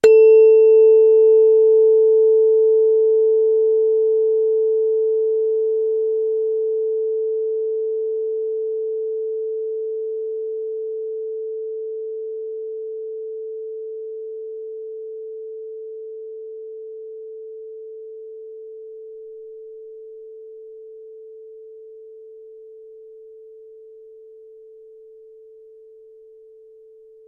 Diapasón
O diapasón é con seguridade o elemento empregado para afinar instrumentos máis antigo. O seu funcionamento é moi sinxelo, trátase dun aparello de metal que produce un son característico ao ser golpeado.
A súa frecuencia de vibración é sempre a mesma (440Hz), xa que non podemos modificar o son que produce, e a diferenza dos instrumentos de afinación máis modernos, soamente podemos empregalo como unha referencia que escoitamos para afinar despois manualmente.
Tuning-fork-440Hz.mp3